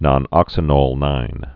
(nŏn-ŏksə-nôlnīn, -nŏl-, -nōl-)